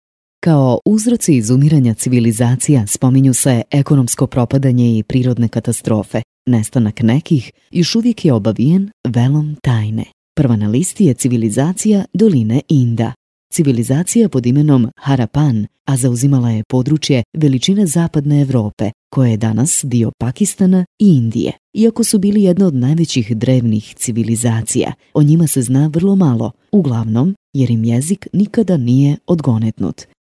Boşnakca Seslendirme
Kadın Ses